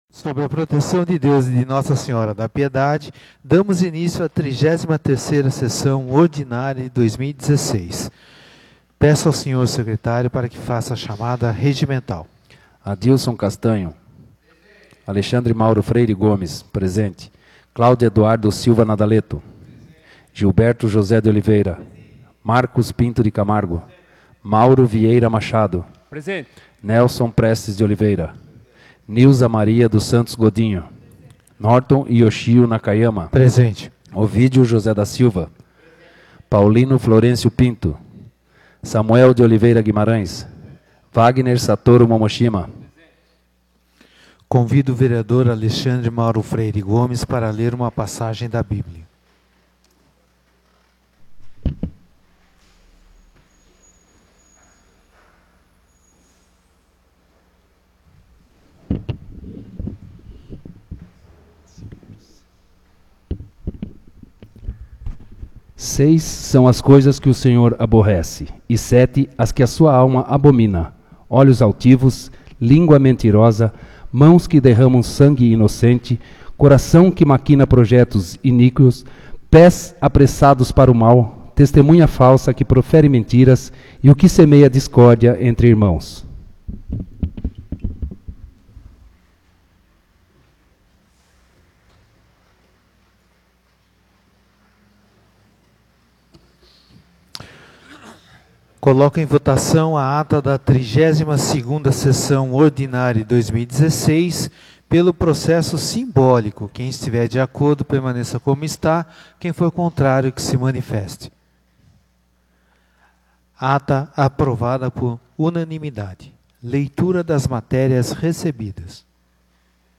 33ª Sessão Ordinária de 2016